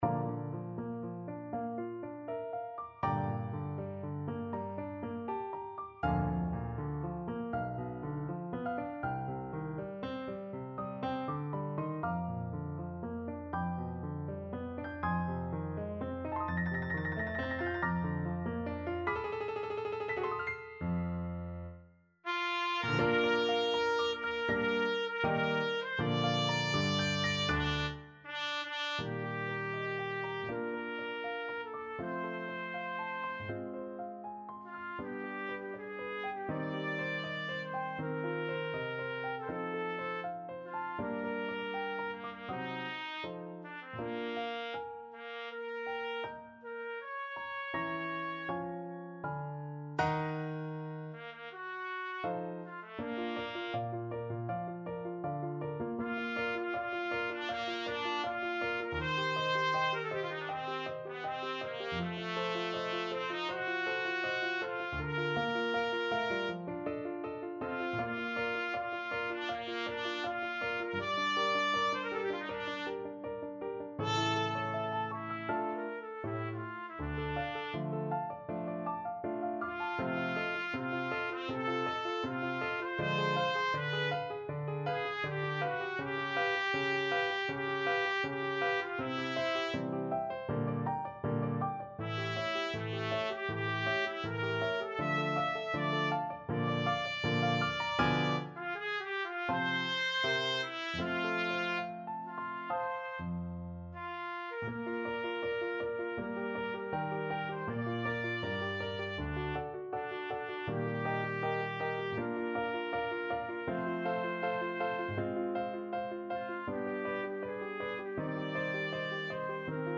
Trumpet
Bb major (Sounding Pitch) C major (Trumpet in Bb) (View more Bb major Music for Trumpet )
4/4 (View more 4/4 Music)
~ = 100 Andante con moto =80 (View more music marked Andante con moto)
Classical (View more Classical Trumpet Music)